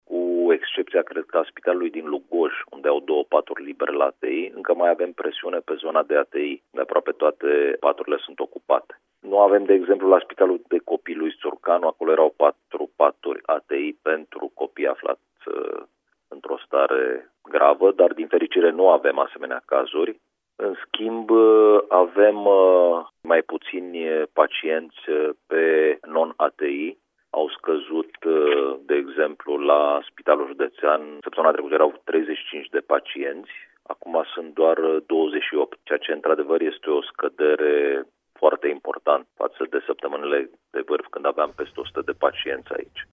Subprefectul de Timiș a subliniat însă la Radio Timișoara că mai sunt probleme cu asigurarea paturilor ATI-COVID.